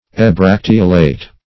ebracteolate - definition of ebracteolate - synonyms, pronunciation, spelling from Free Dictionary
Search Result for " ebracteolate" : The Collaborative International Dictionary of English v.0.48: Ebracteolate \E*brac"te*o*late\, a. [Pref. e- + bracteolate.]